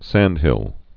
(săndhĭl)